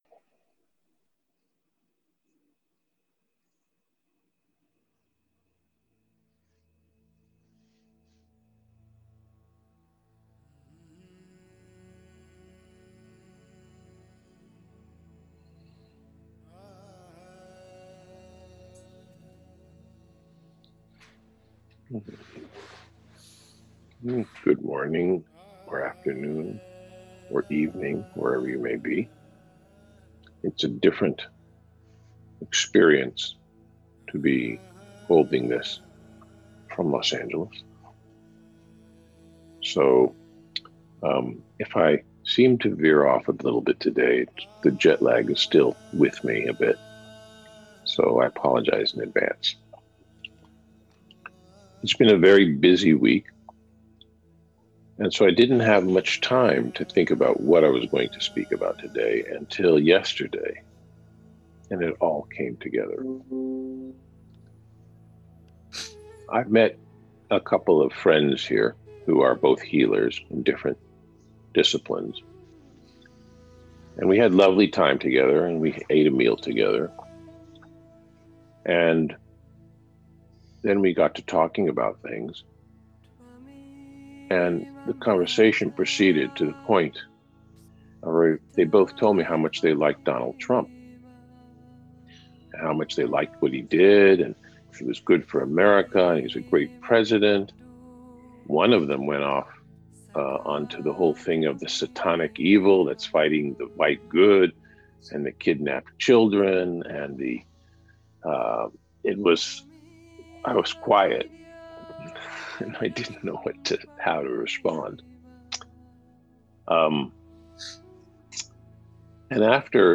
Path to Wisdom :: Conversation